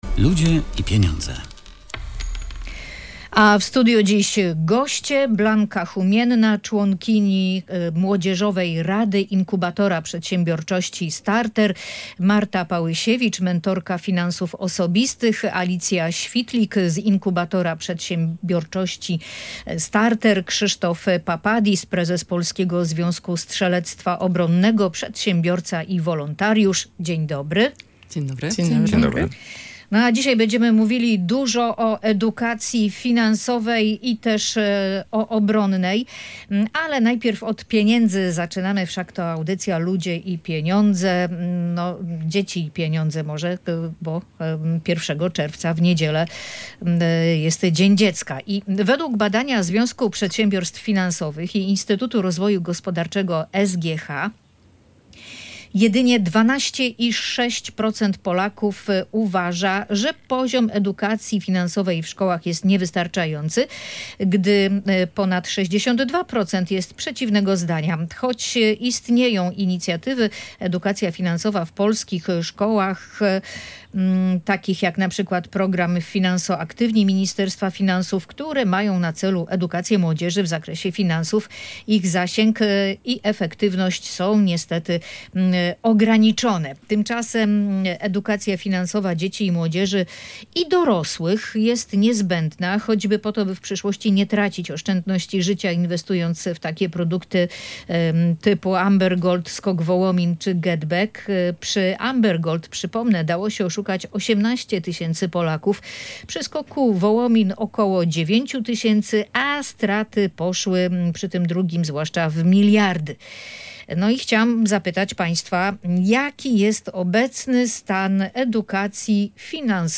Posłuchaj dyskusji ekspertów